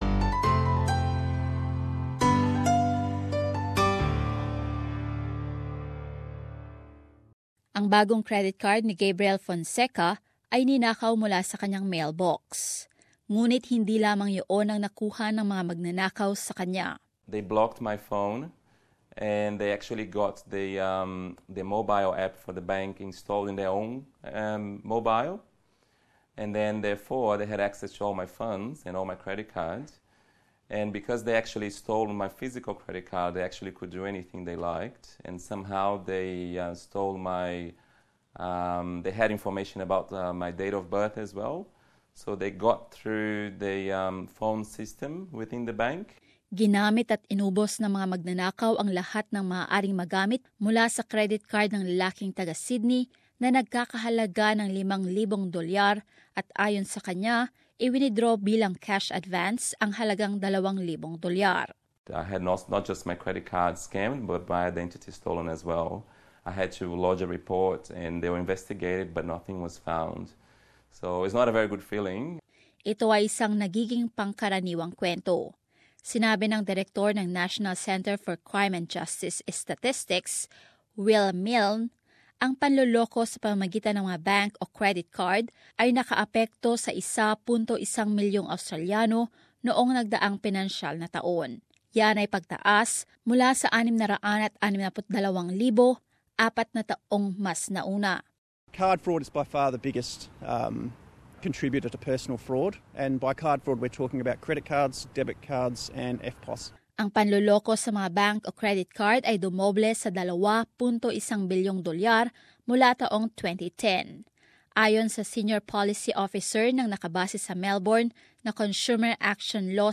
As this report explains, it is costing a total of $3 billion, with two- thirds of that relating to card fraud.